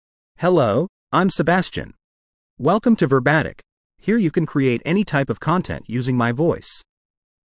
MaleEnglish (United States)
Sebastian is a male AI voice for English (United States).
Voice sample
Sebastian delivers clear pronunciation with authentic United States English intonation, making your content sound professionally produced.